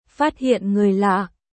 1. Âm thanh lời chào tiếng việt